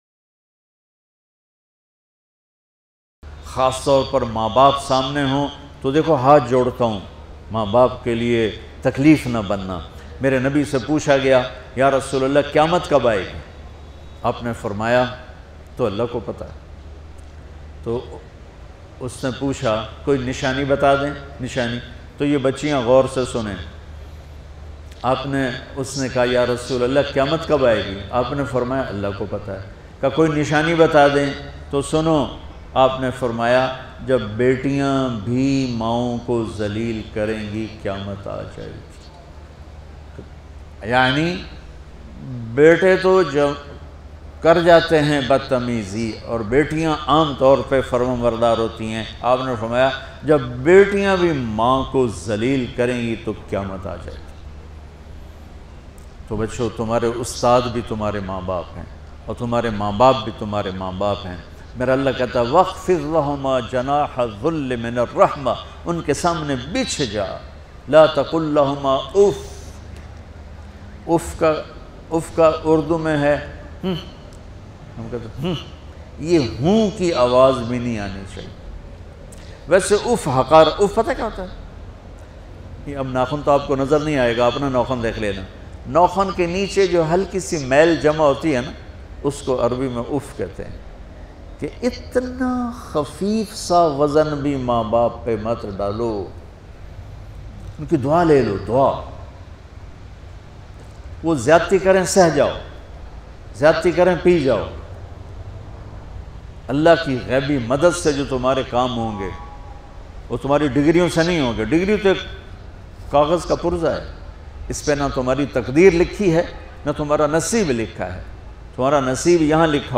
Maan Ki Khidmat Ka Sila such a Emotional Bayan Maulana Tariq Jameel Latest Bayan, Listen Online download.